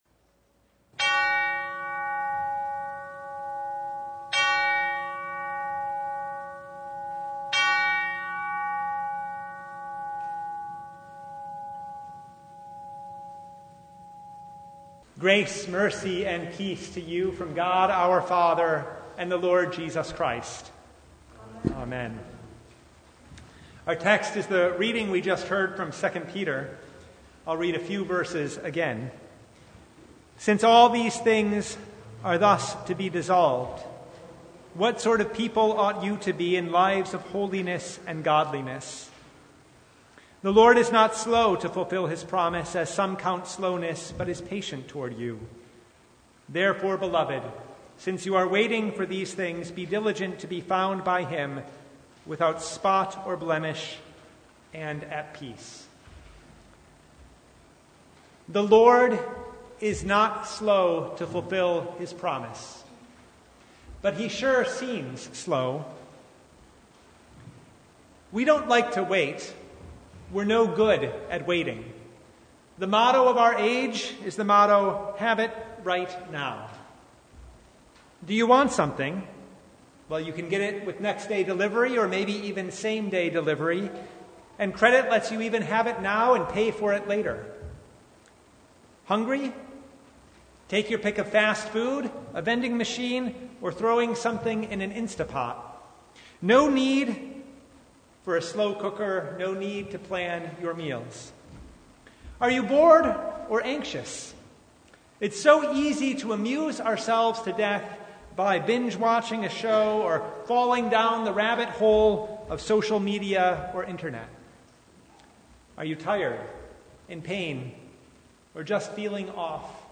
Service Type: Advent Vespers